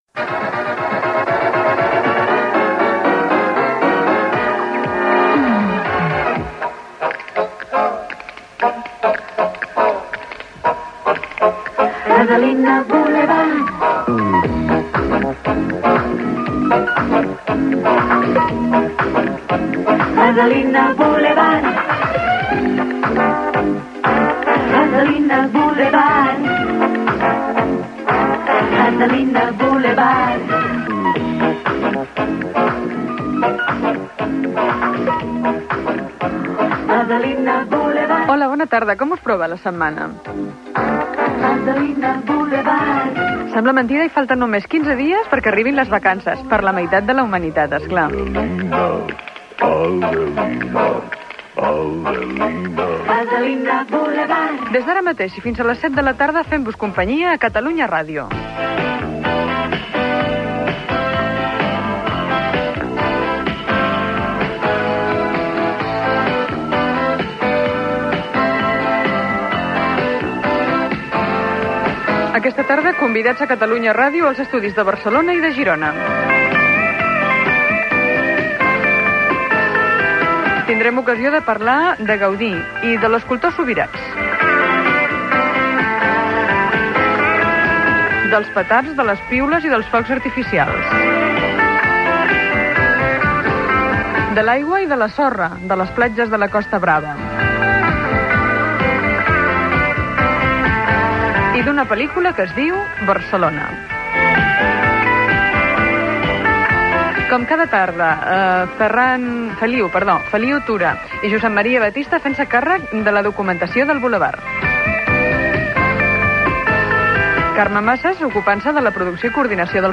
Inici del programa amb la sintonia cantada, presentació, sumari de continguts i equip
Entreteniment